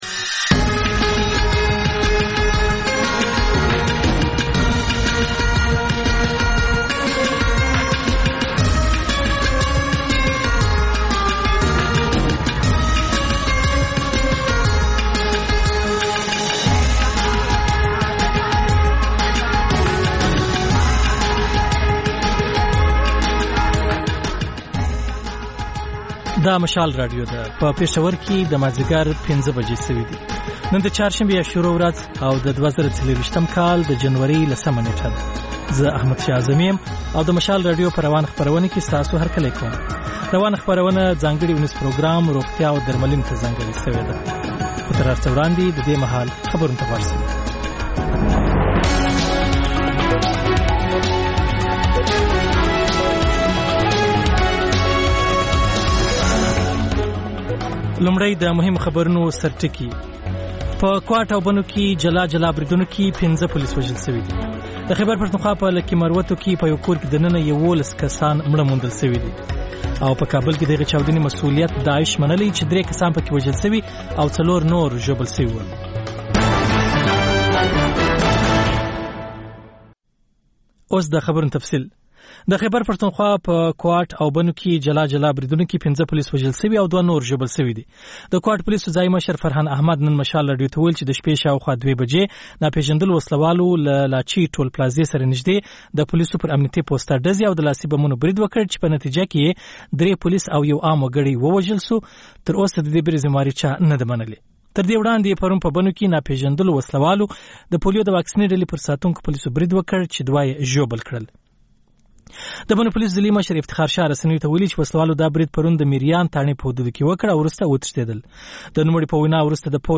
د مشال راډیو ماښامنۍ خپرونه. د خپرونې پیل له خبرونو کېږي، بیا ورپسې رپورټونه خپرېږي.